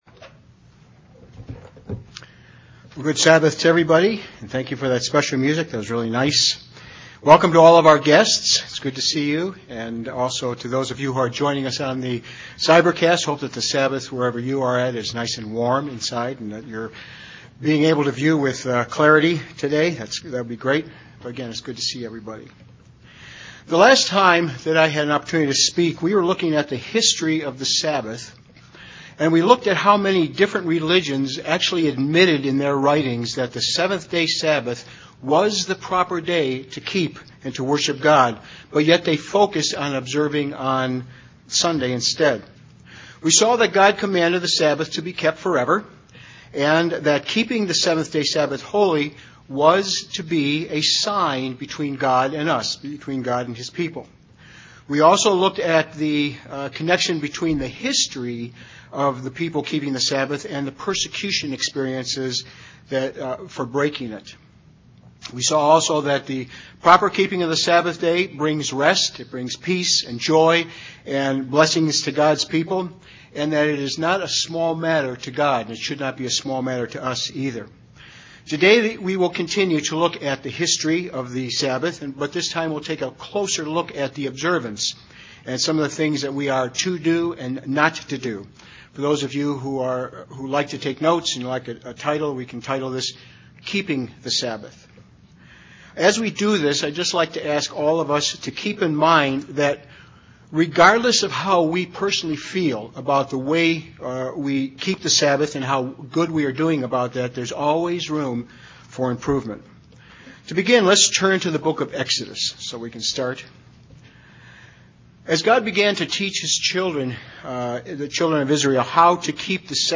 Given in North Canton, OH
UCG Sermon Studying the bible?